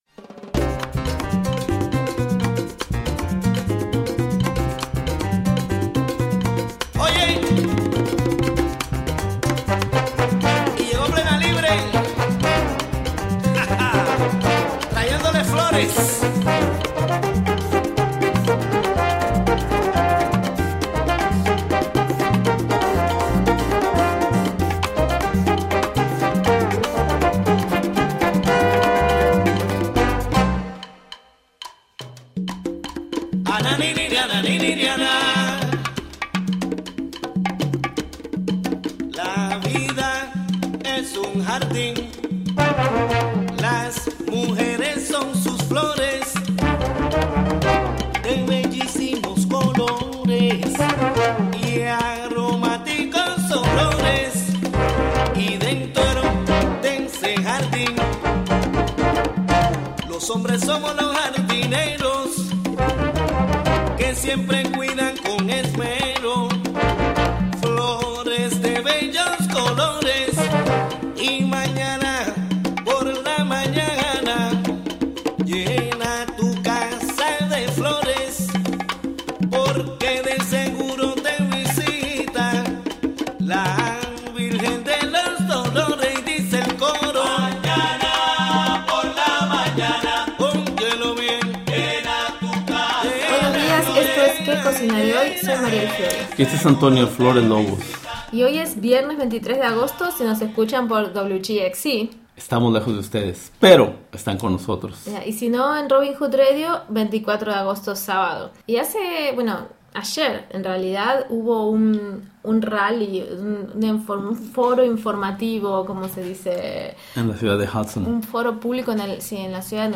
Also, an interview